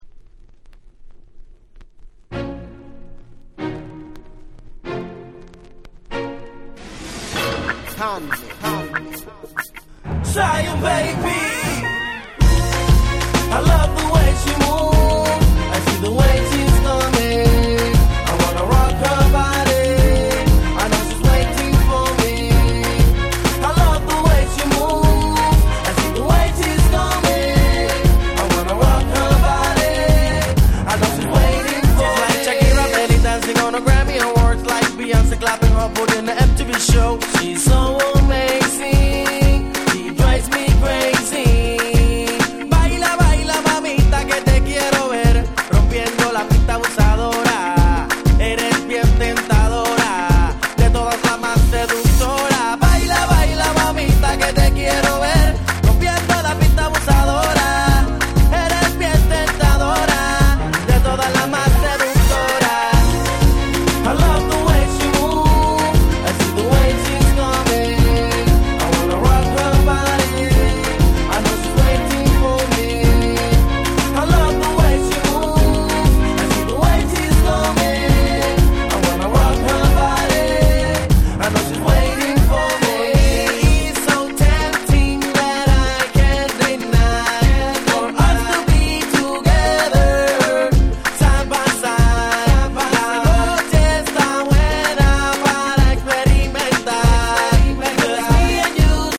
07' Smash Hit Reggaeton / Latin !!